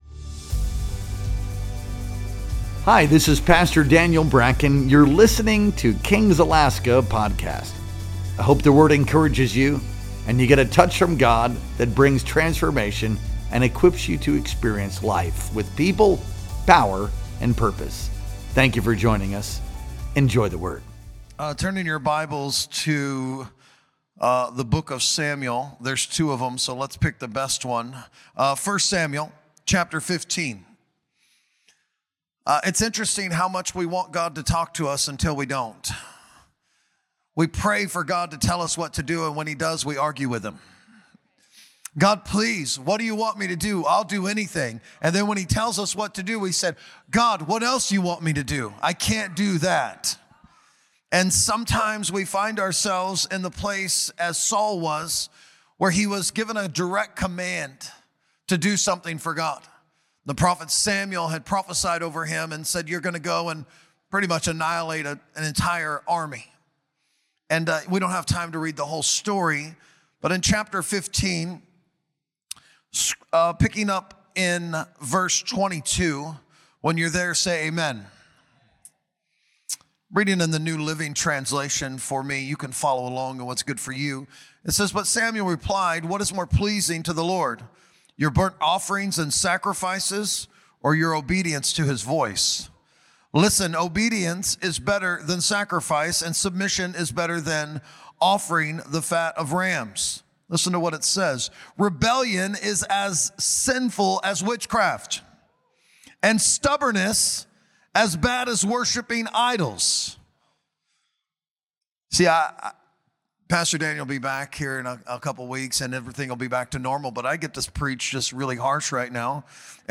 Our Sunday Night Worship Experience streamed live on November 9th, 2025.